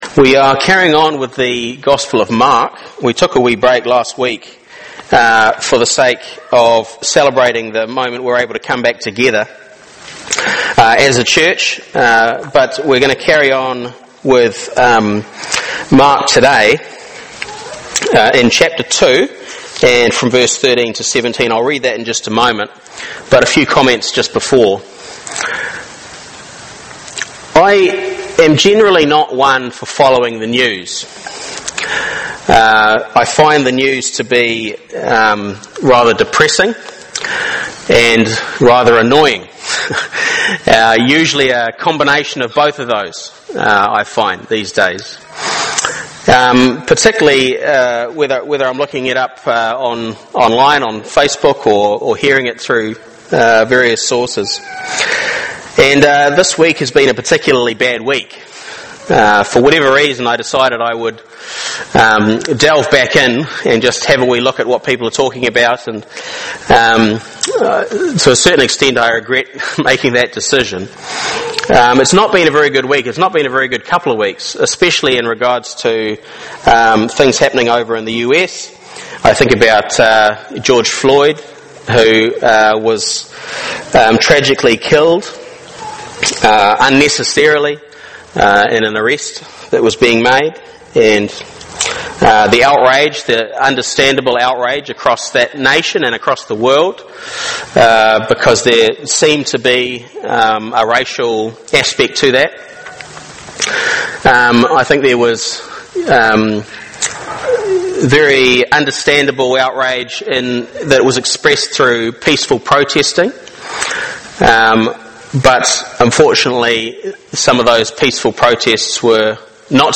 Mark 2:13-17 Service Type: Family